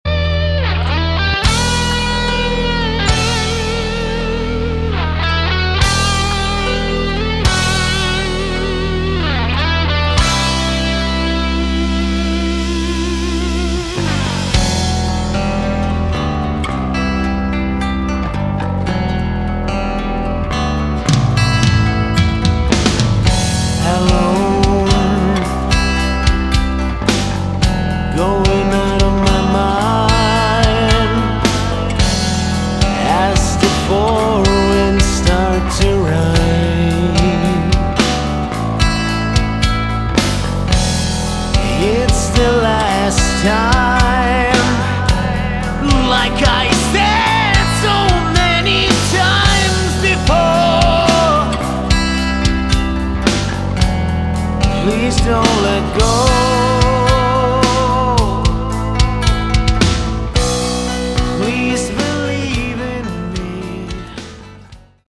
Category: Melodic Metal
lead vocals
guitars, backing vocals
bass, backing vocals
drums